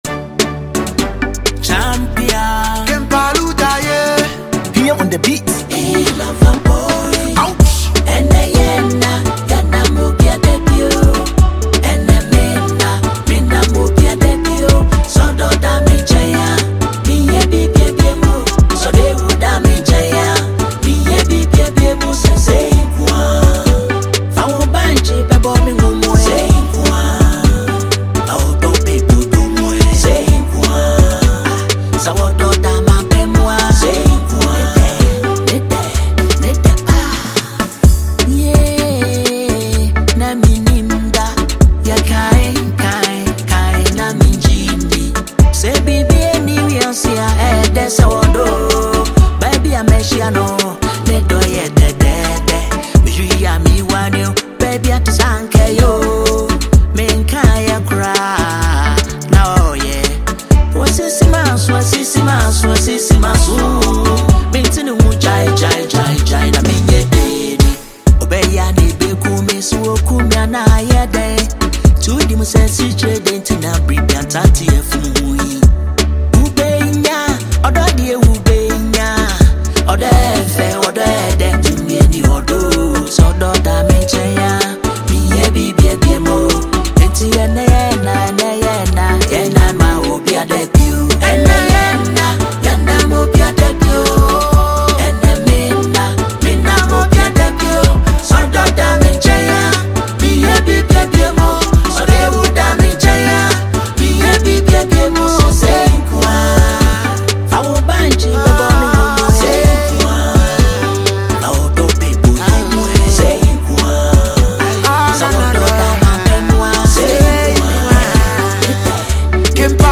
• Genre: Highlife / Hip-hop fusion